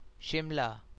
1. ^ English: /ˈʃɪmlə/; Hindi: [ˈʃɪmla]